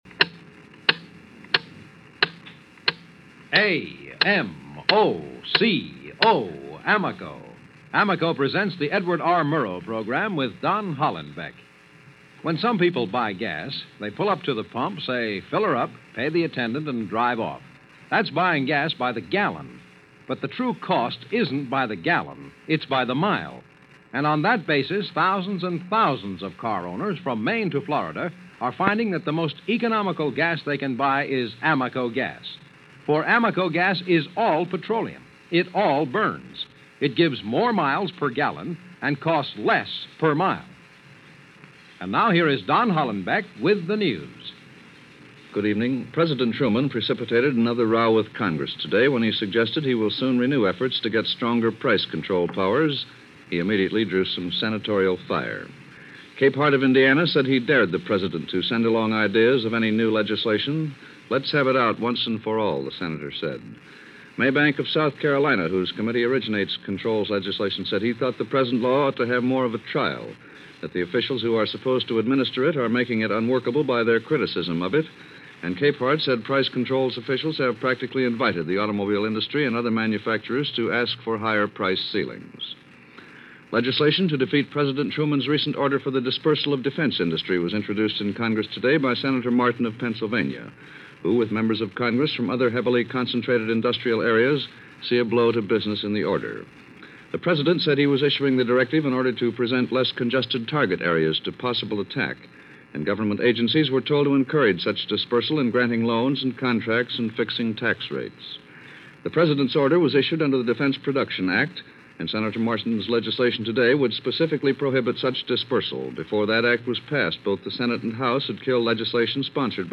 Don Hollenbeck and the News